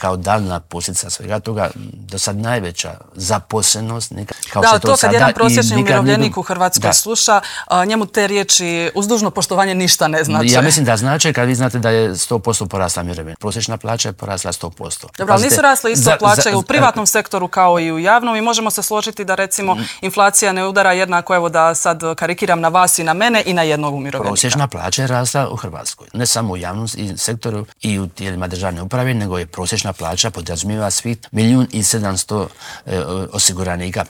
ZAGREB - U nedjelju je obilježena šesta godišnjica zagrebačkog potresa, što se još treba napraviti po pitanju poslijepotresne obnove u Intervjuu tjedna Media servisa pitali smo potpredsjednika Vlade i ministra prostornog uređenja, graditeljstva i državne imovine Branka Bačića.